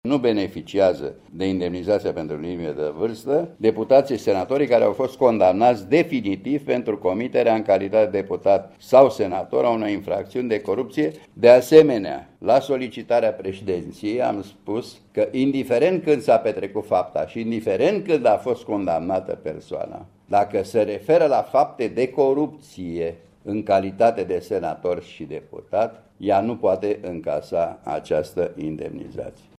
Nu o vor primi, însă, cei care au fost condamnaţi definitiv pentru fapte de corupţie, spune preşedintele comisiei pentru statutul parlamentarilor, Bogdan Niculescu Duvăz.